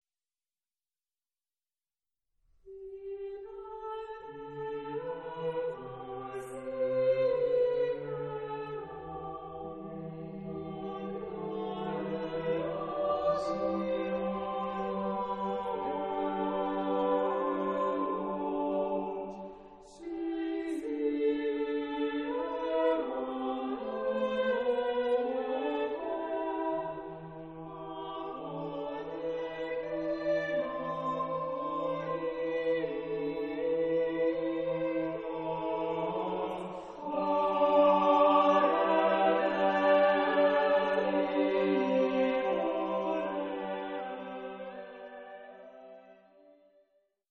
Christmas Music from the Byzantine Tradition